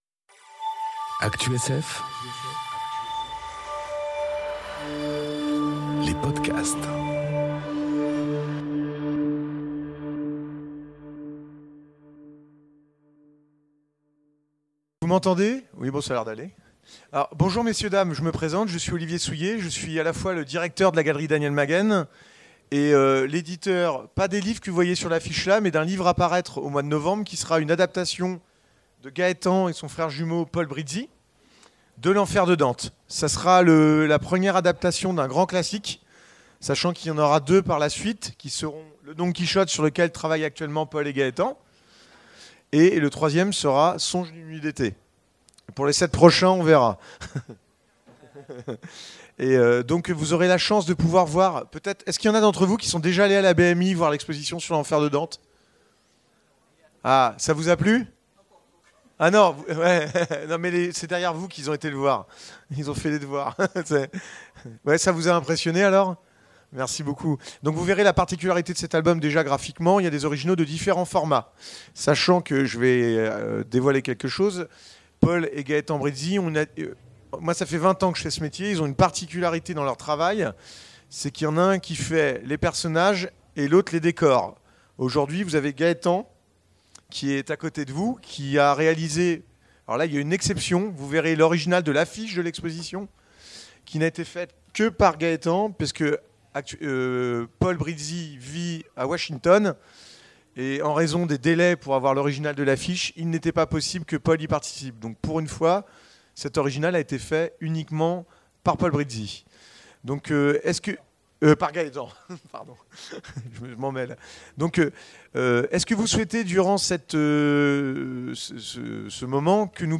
À l'occasion des Imaginales 2022, on vous propose de (ré)écoutez la table-ronde Le Grand entretien en compagnie de l'auteur de l'affiche des Imaginales 2022 : Gaétan Brizzi.